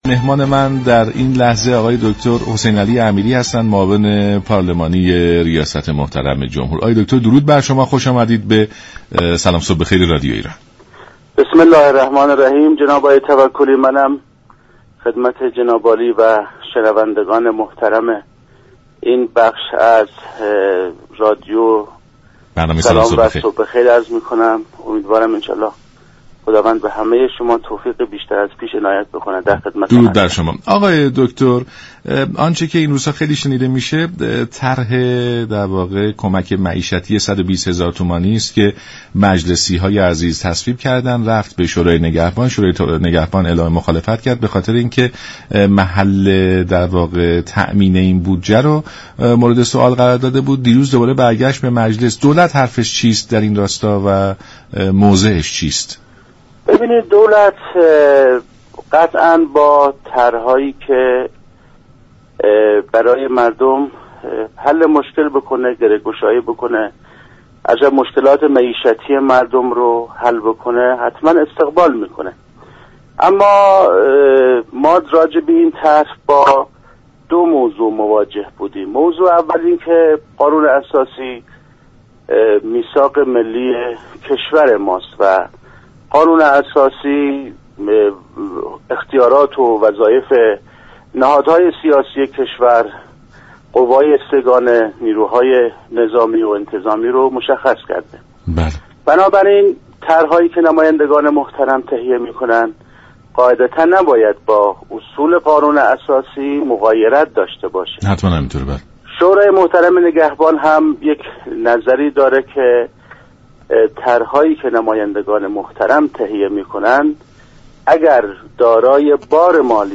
به گزارش شبكه رادیویی ایران، دكتر حسینعلی امیری معاون پارلمانی ریاست جمهور در برنامه «سلام صبح بخیر» از موضع دولت به طرح كمك معیشتی 120 هزار تومانی مجلس سخن گفت و با بیان اینكه دولت از طرح هایی كه باعث گره گشایی كار مردم شود به طور قطع استقبال می كند، افزود: طرح های مصوب شده در مجلس شورای اسلامی نباید با اصول قانون اساسی مغایرت داشته باشد.